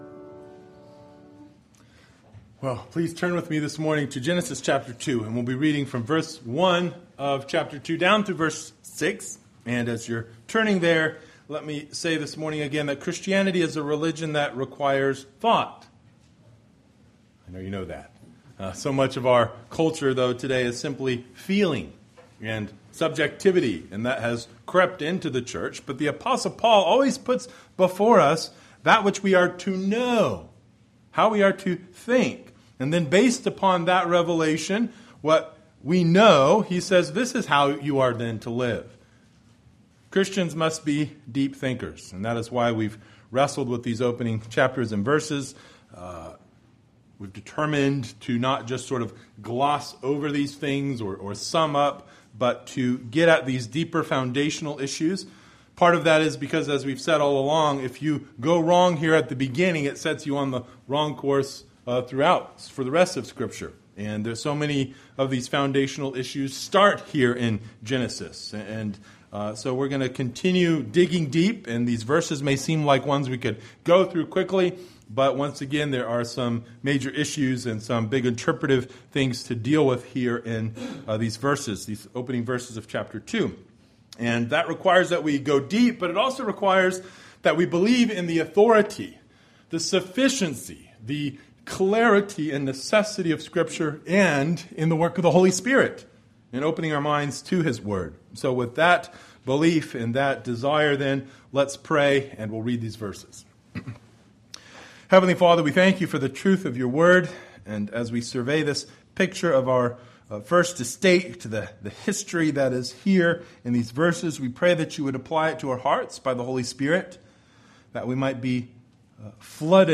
Passage: Genesis 2:1-6 Service Type: Sunday Morning